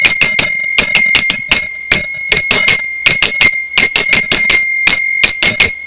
Combinación un poco más complicada, también se alternan las manos.
takaDUM-takatakaDUM -DUM- takaDUM //